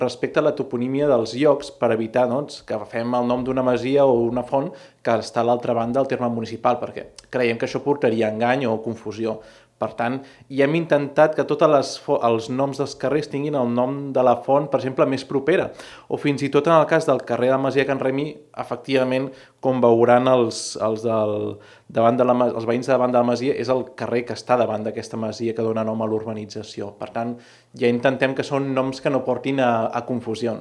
El regidor de Patrimoni, Bernat Calvo, explica que s'ha respectat la toponímia dels llocs i la seva proximitat per evitar confusions.